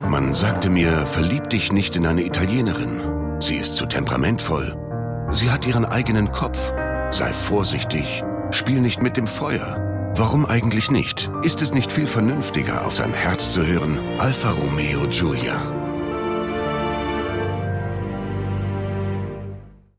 dunkel, sonor, souverän
Mittel plus (35-65)
Berlinerisch, Norddeutsch
Commercial (Werbung)